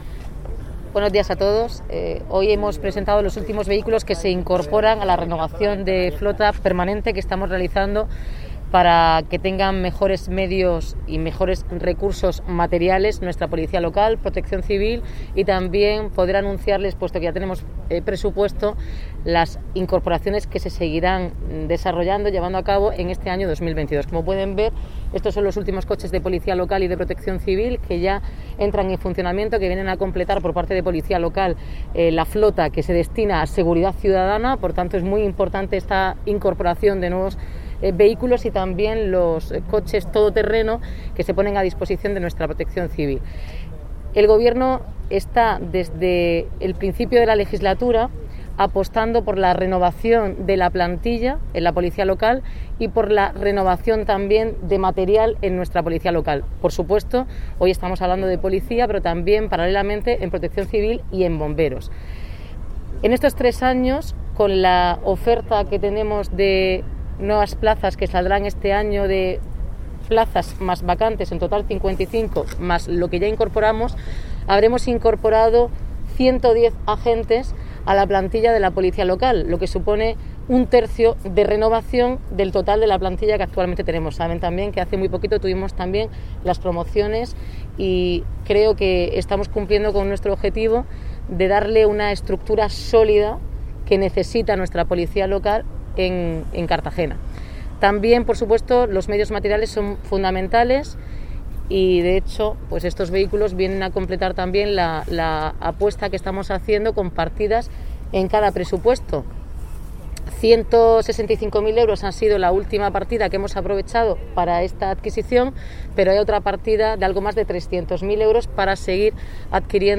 Protección Civil y Policía Local de Cartagena disponen desde este lunes, 24 de enero, de seis nuevos vehículos para mejorar la seguridad de los cartageneros, que ha presentado esta mañana en la Plaza del Ayuntamiento la alcaldesa, Noelia Arroyo, acompañada por la vicealcaldesa, Ana Belén Castejón y el concejal de Seguridad Ciudadana, Juan Pedro Torralba.